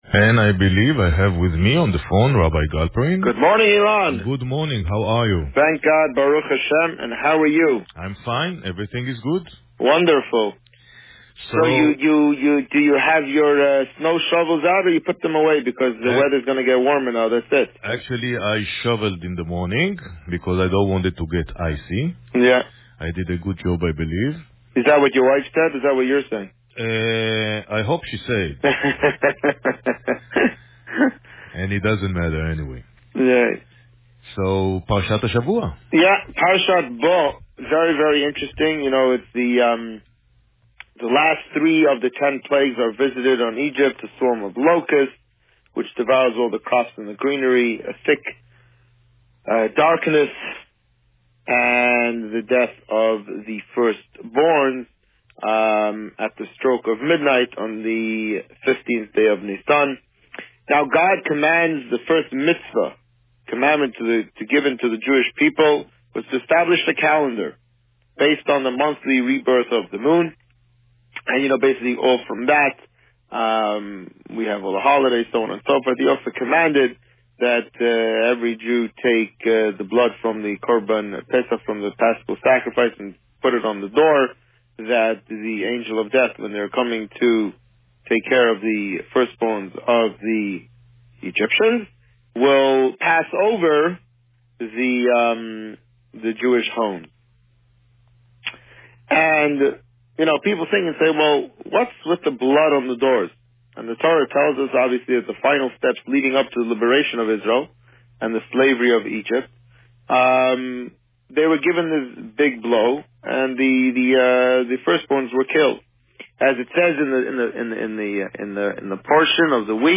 This week, the Rabbi spoke about Parsha Bo. Listen to the interview here.